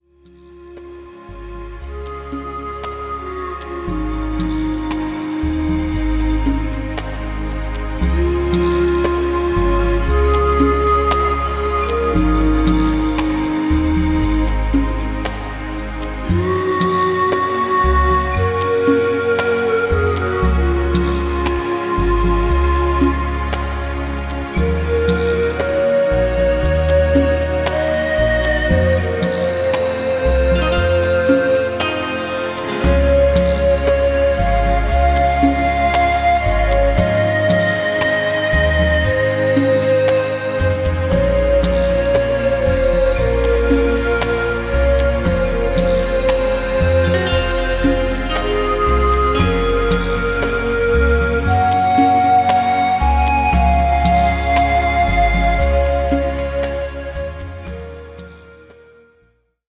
Naturgeräusche sind auch mit an Bord.